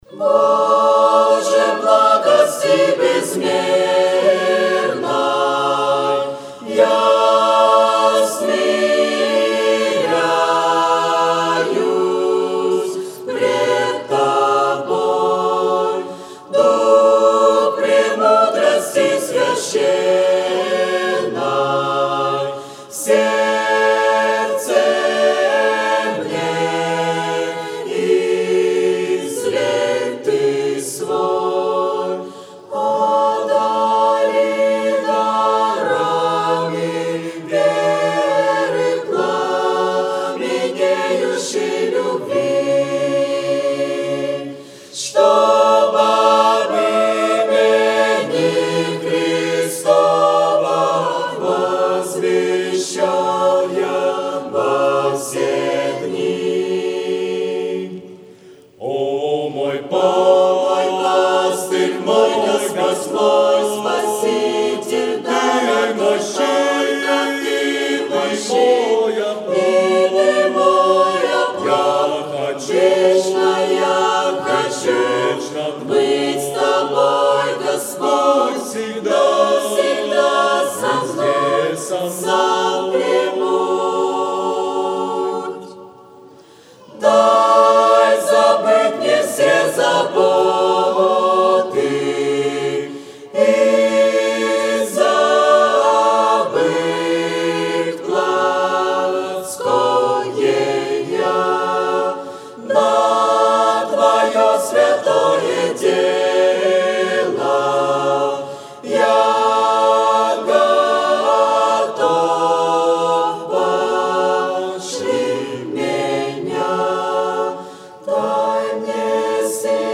03-24-24 Воскресение — Церковь «Путь ко Спасению»
04+Хор+-+Боже+благости+безмерной.mp3